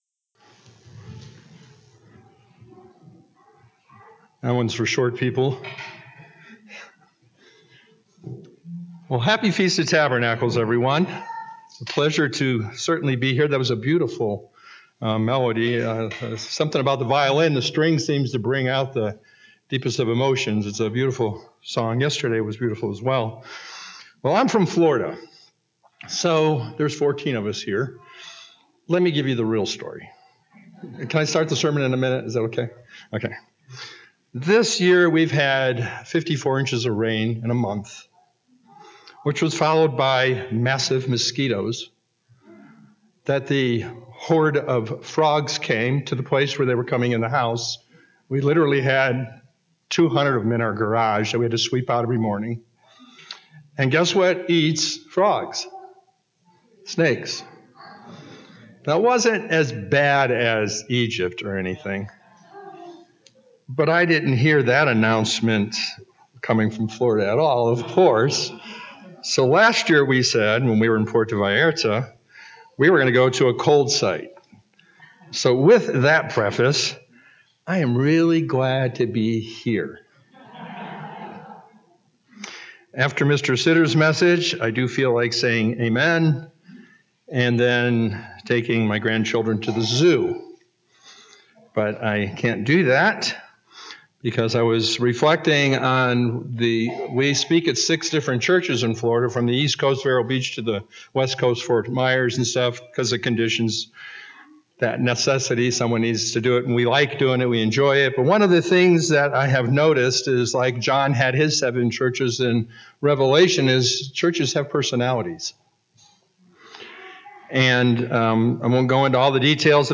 This sermon was given at the Cochrane, Alberta 2019 Feast site.